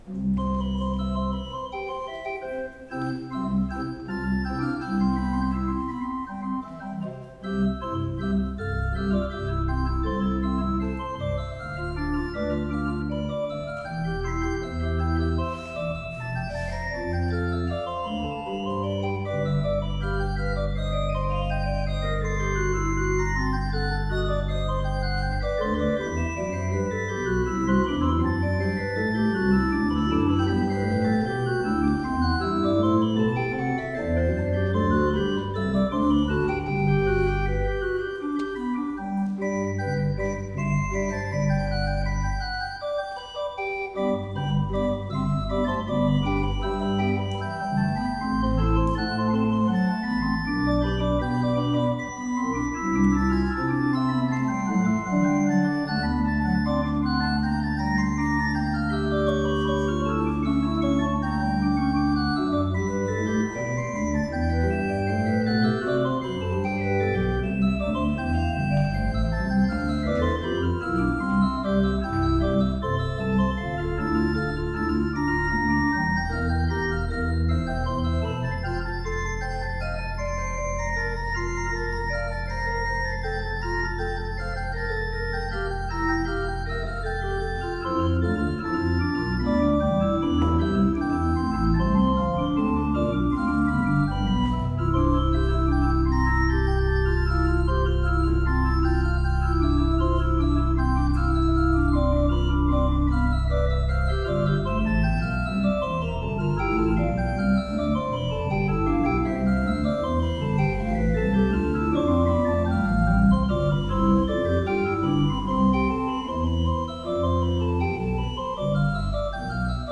notre organiste
Le deuxième dimanche de Pâques, elle nous a joué une sonate en trois mouvements de Bach pour marquer l’occasion.